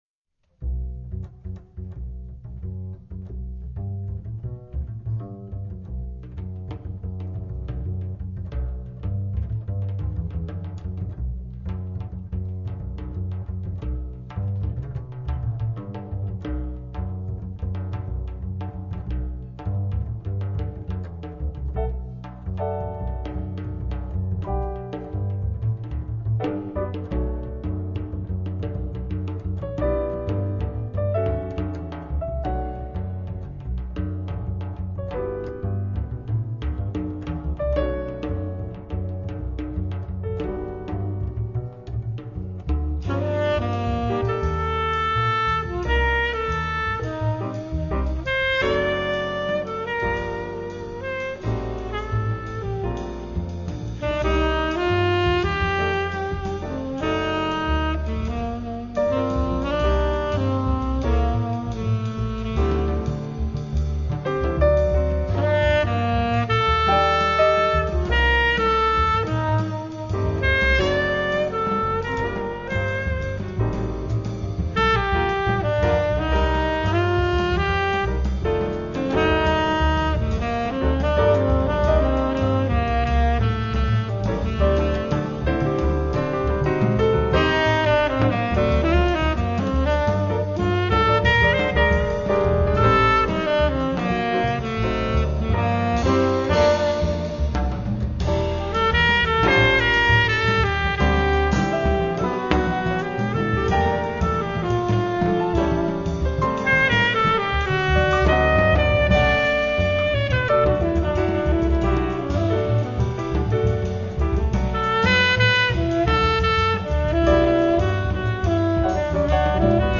frutto di una registrazione live
Un loop di basso, semplice